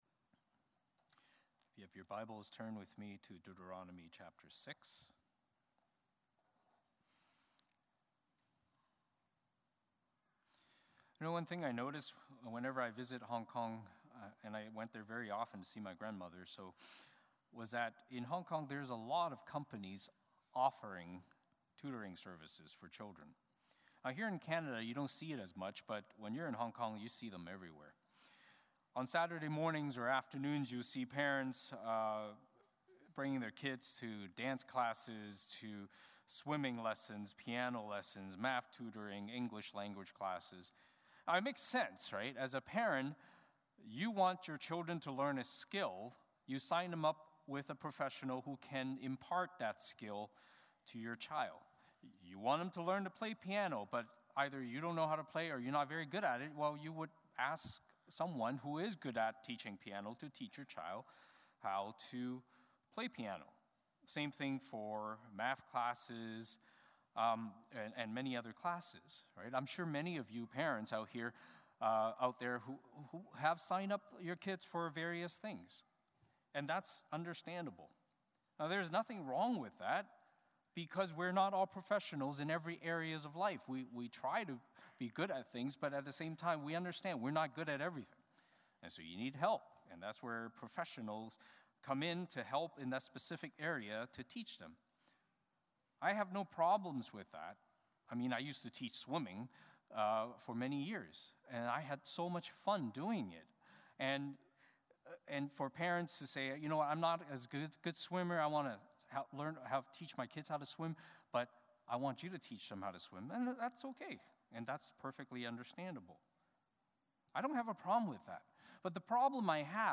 Service Type: Sunday Morning Service Passage